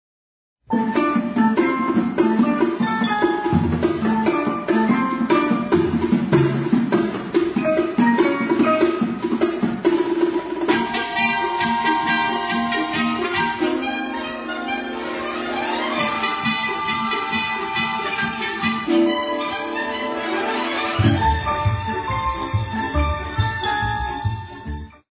show tune-like